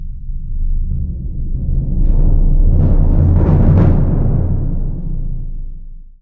BIG BD 2B -L.wav